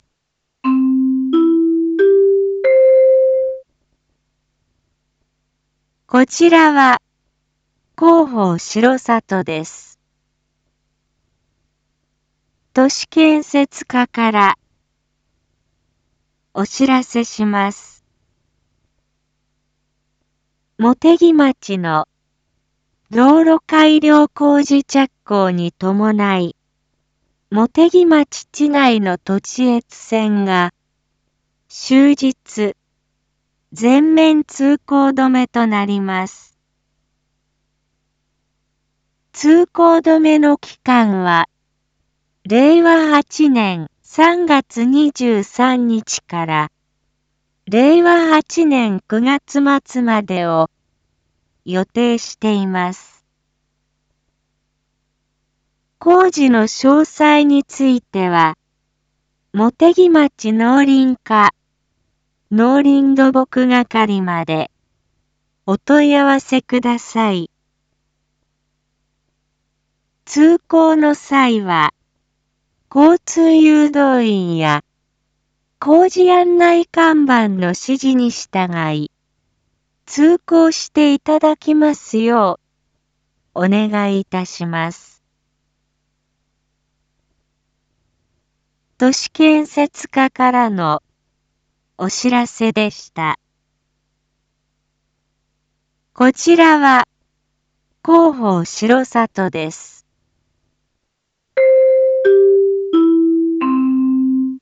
一般放送情報
Back Home 一般放送情報 音声放送 再生 一般放送情報 登録日時：2026-03-21 19:01:46 タイトル：茂木町林道土地越線道路改良工事② インフォメーション：こちらは広報しろさとです。